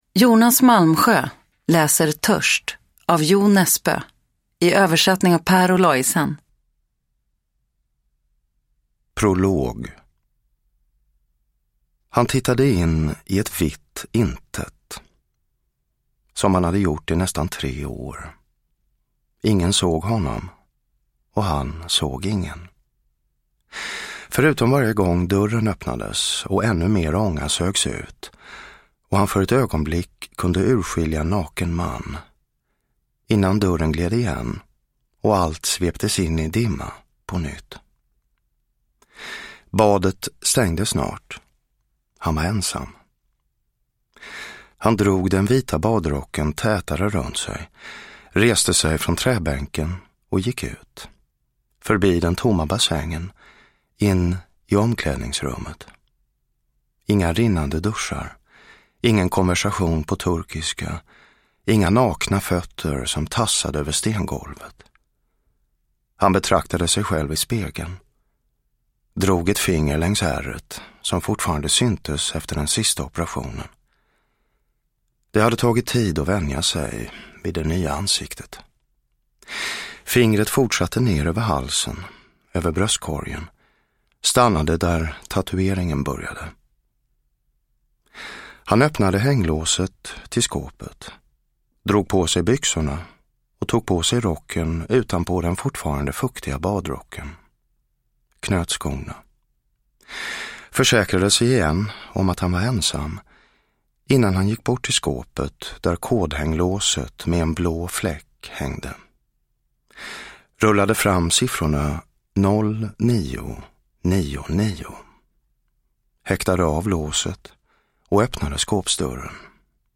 Törst – Ljudbok – Laddas ner
Uppläsare: Jonas Malmsjö